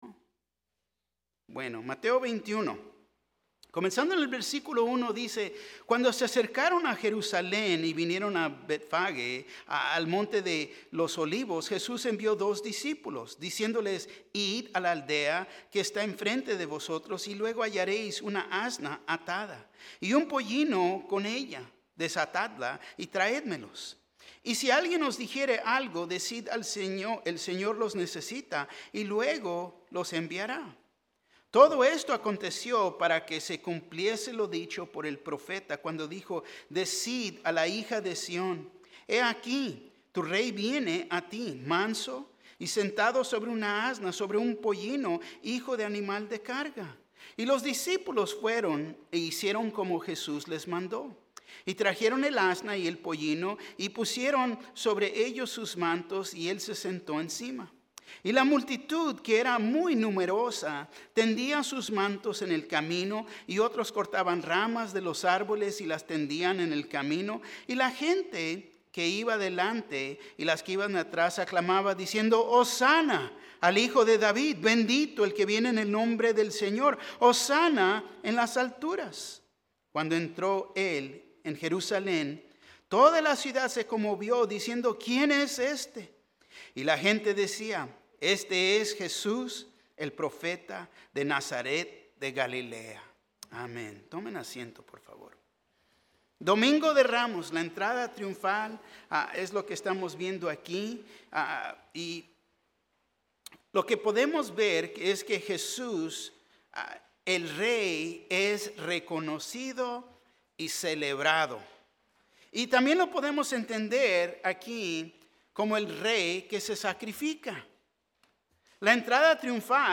Un mensaje de la serie "Liberados."